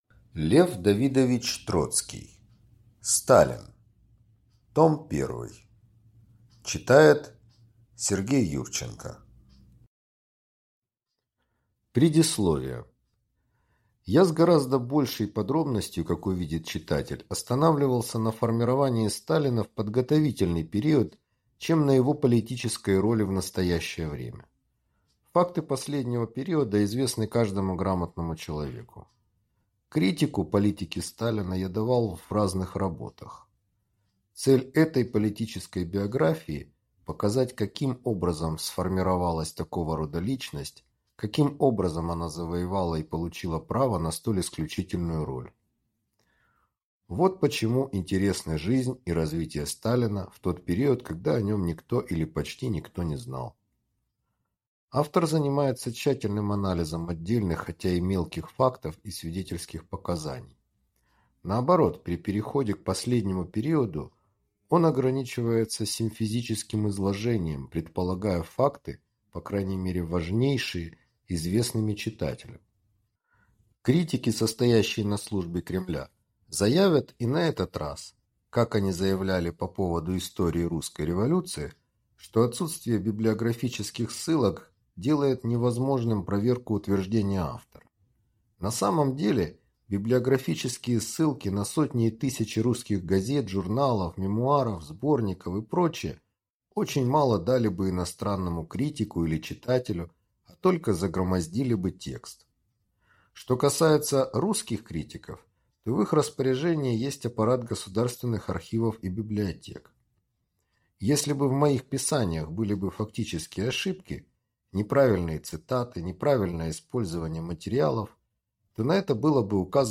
Аудиокнига Сталин. Том I | Библиотека аудиокниг